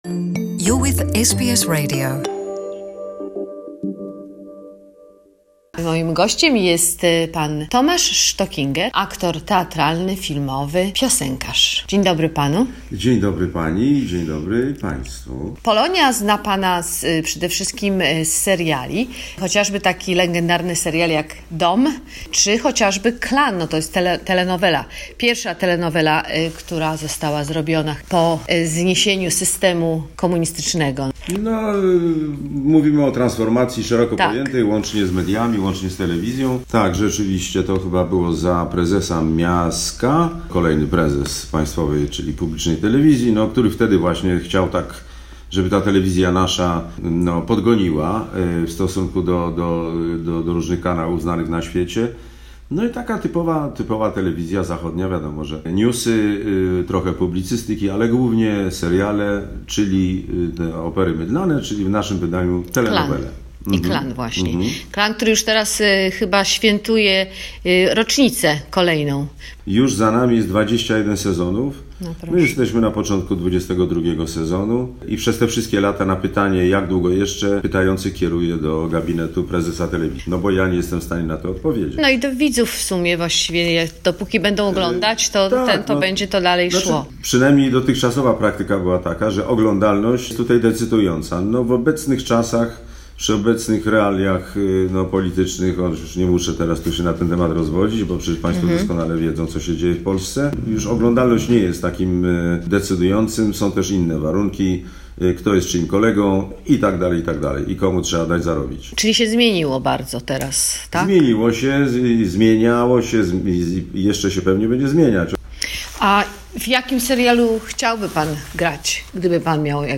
Rozmowa z aktorem Tomaszem Stockingerem